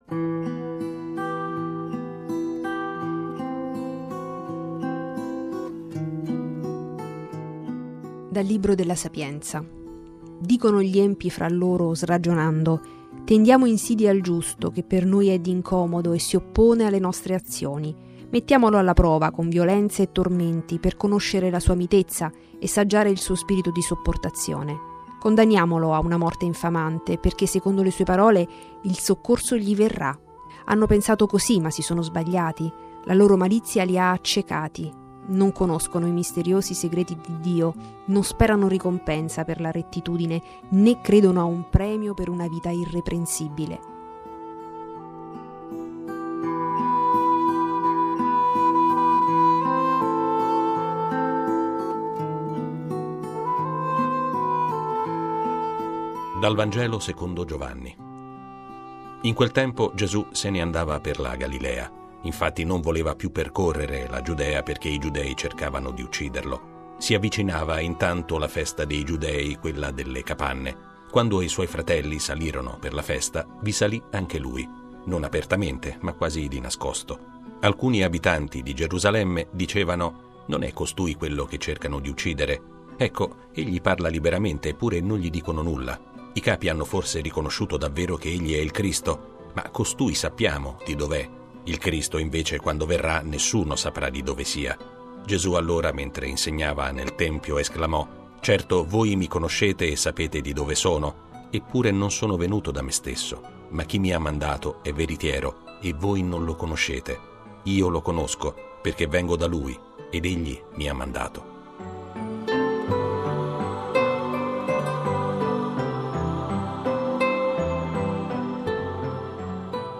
Le letture del giorno (prima e Vangelo) e le parole di Papa Francesco da VaticanNews: